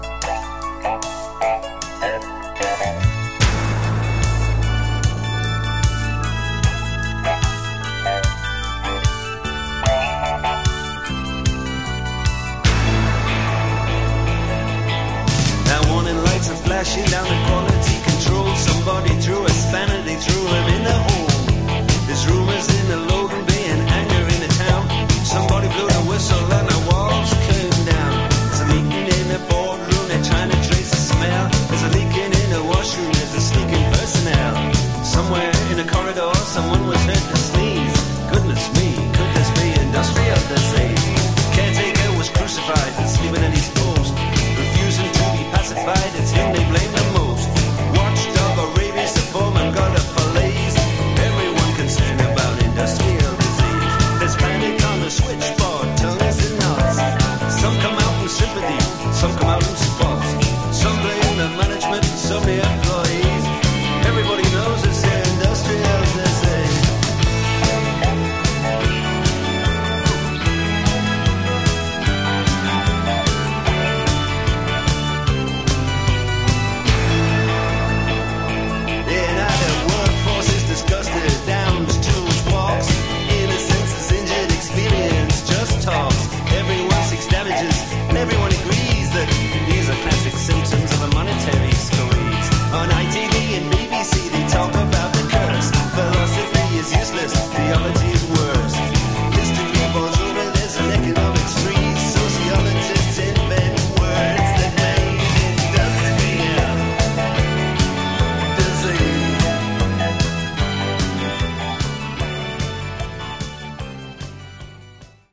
Genre: Modern Rock